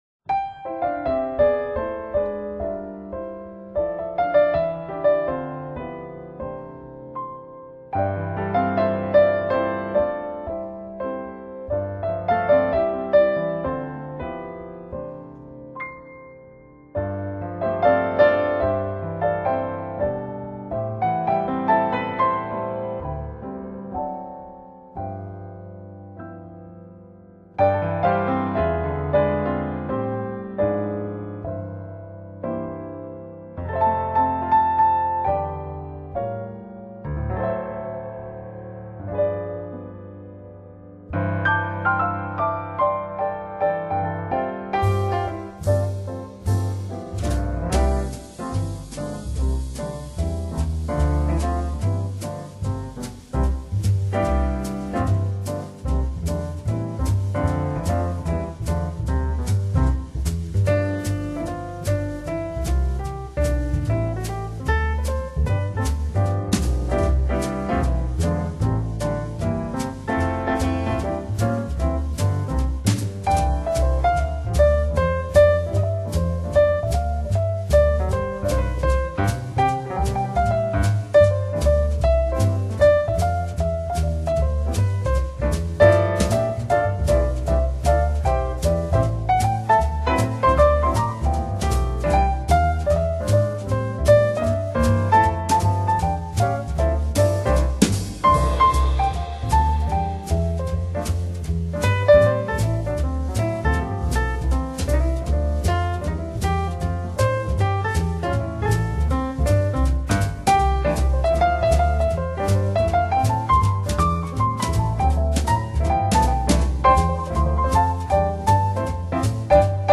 Genre: Jazz, New Age, Christmas